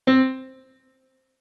MIDI-Synthesizer/Project/Piano/40.ogg at 51c16a17ac42a0203ee77c8c68e83996ce3f6132